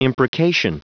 Prononciation du mot imprecation en anglais (fichier audio)